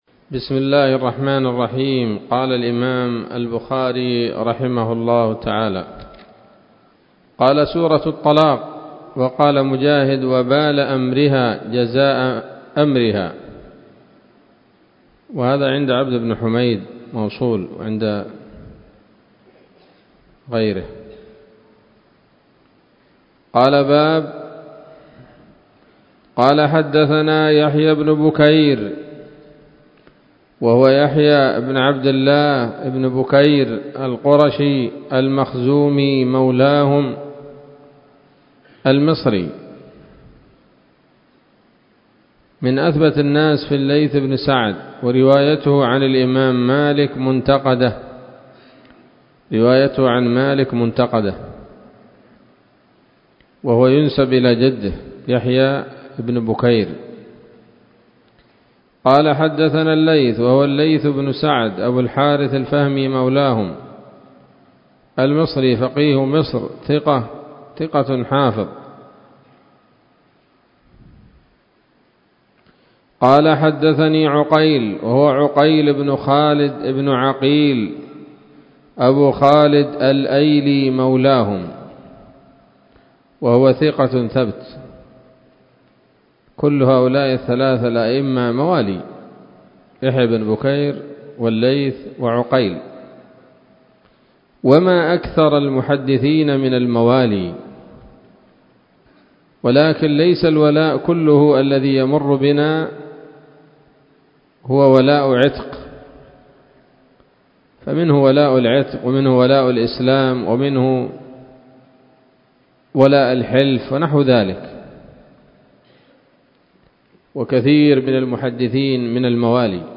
الدرس السادس والستون بعد المائتين من كتاب التفسير من صحيح الإمام البخاري